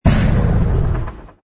engine_pi_freighter_kill.wav